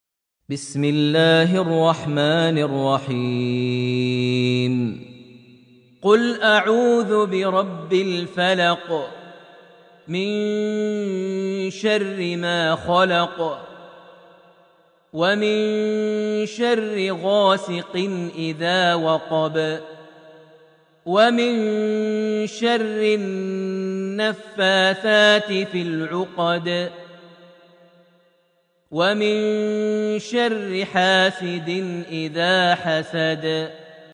surat Alfalaq > Almushaf > Mushaf - Maher Almuaiqly Recitations